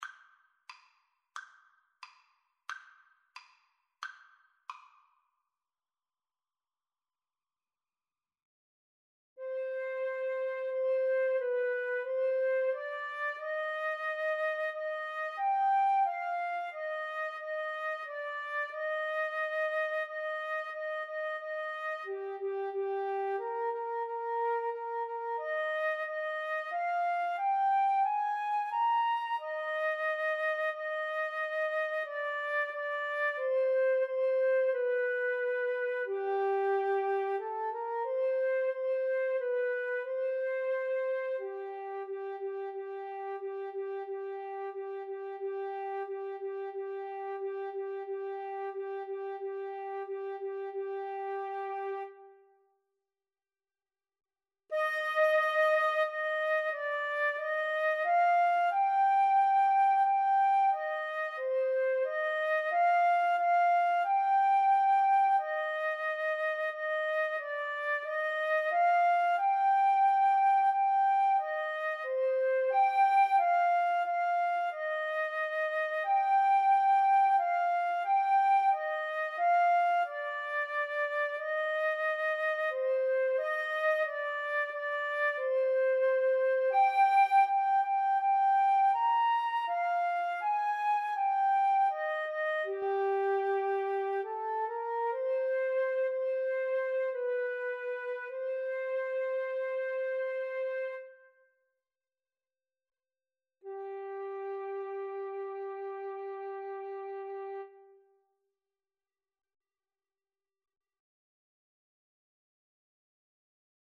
FluteAlto Saxophone
2/4 (View more 2/4 Music)
=90 Allegretto, ma un poco lento
Classical (View more Classical Flute-Saxophone Duet Music)